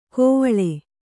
♪ kōvaḷe